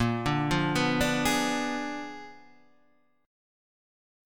A#7b9 chord {6 5 3 4 3 4} chord